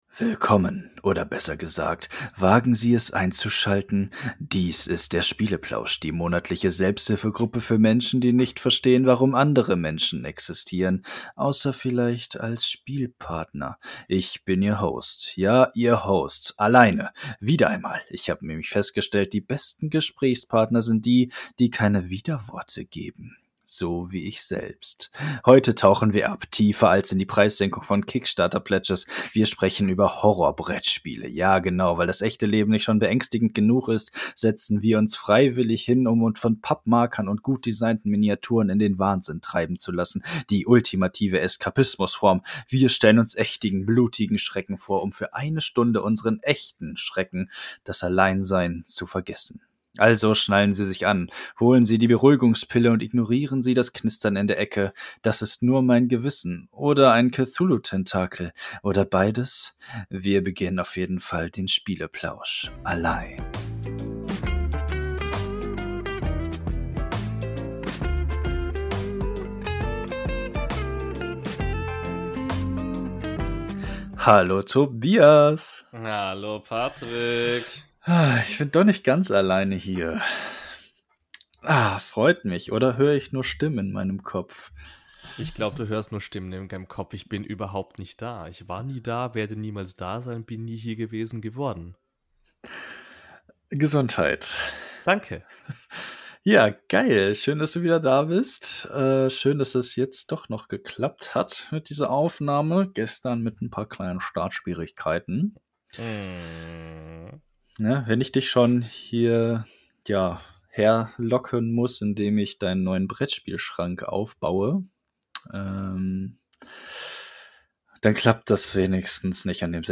Wir wollen euch mit unseren lieblichen Stimmen beglücken, ganz nach dem Motto: Legt euch zurück und entspannt.